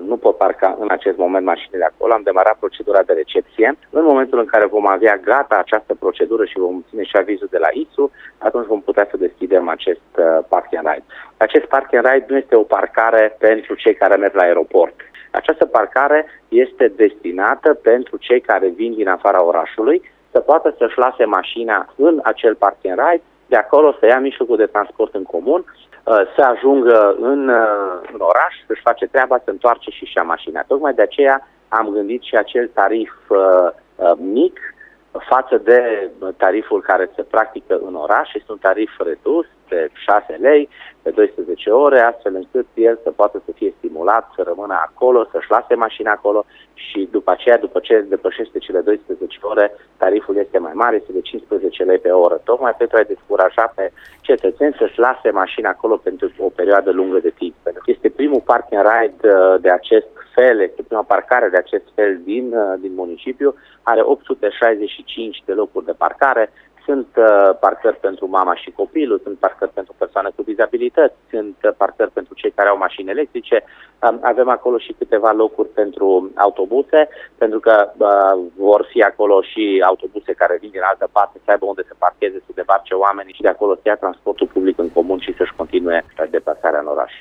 Dan Tarcea, în direct la Radio Cluj: